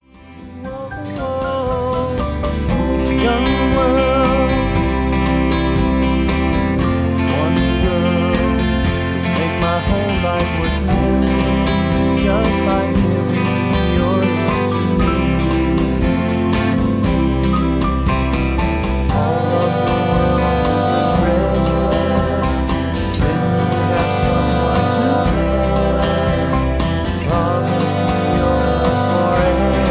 (Key-C) Karaoke MP3 Backing Tracks
Just Plain & Simply "GREAT MUSIC" (No Lyrics).